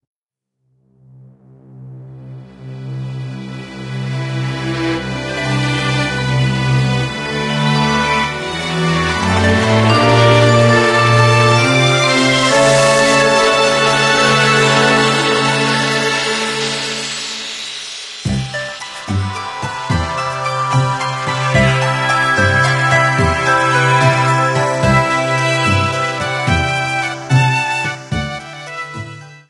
A song
Ripped from the game